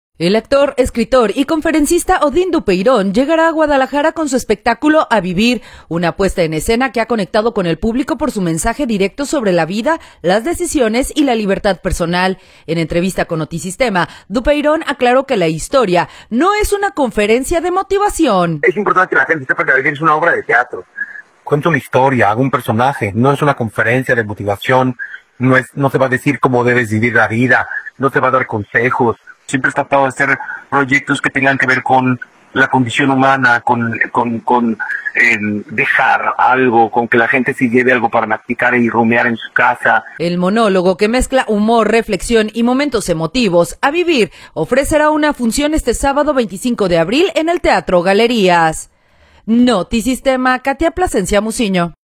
En entrevista con Notisistema, Dupeyrón aclaró que la historia no es una conferencia de motivación.